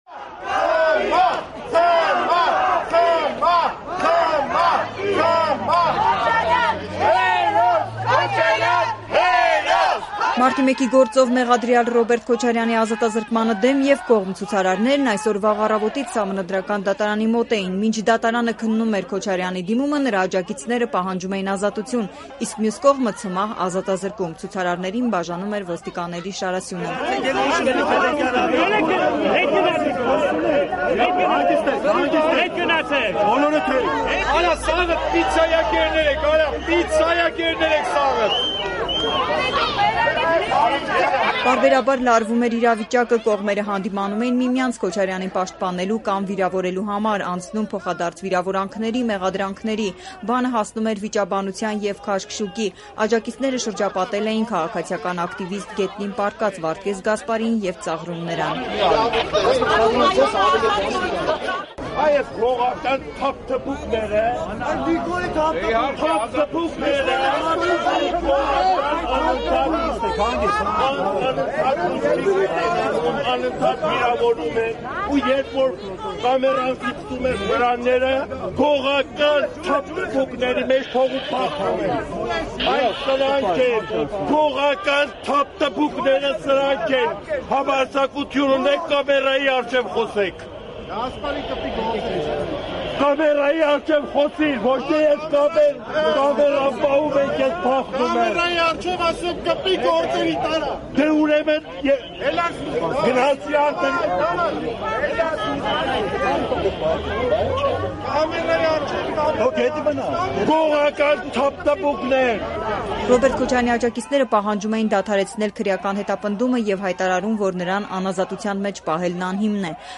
Վիճաբանություն, քաշքշուկ ցուցարարների միջև․ Սահմանադրական դատարանի մոտ թեժ էր
Ռեպորտաժներ